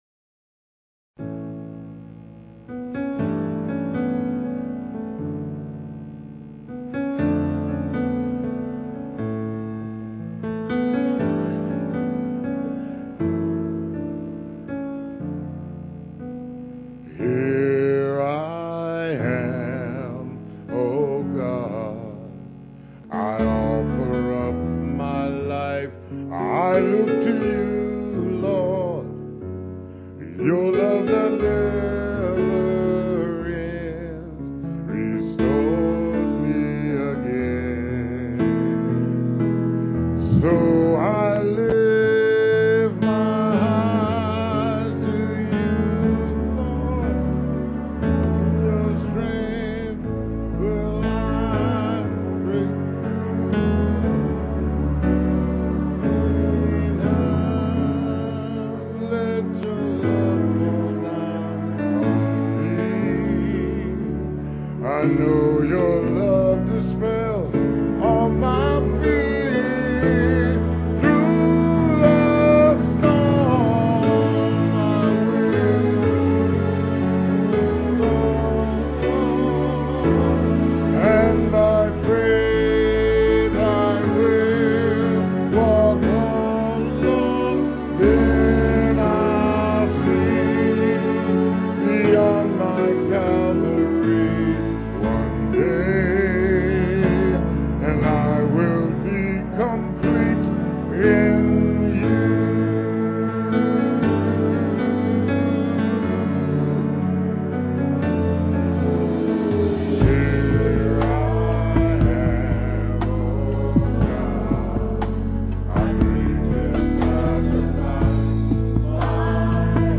Piano and Organ duet